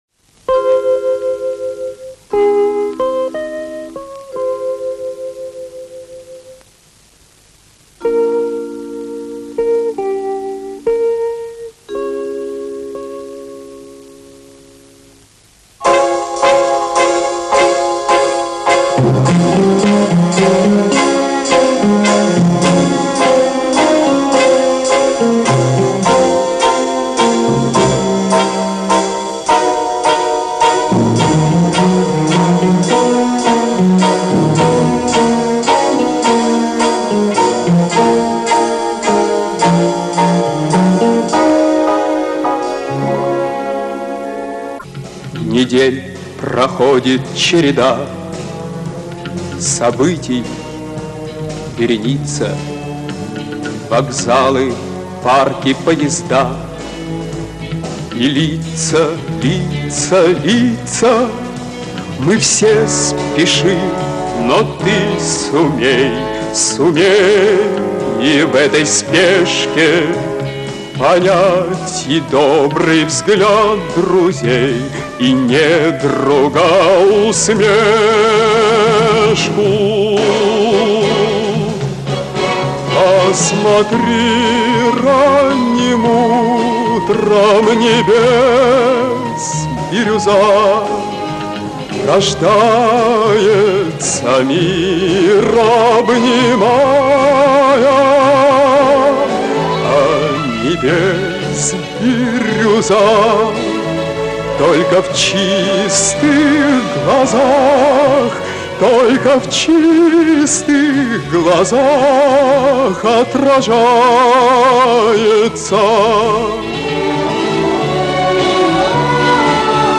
Сам автор поёт получается.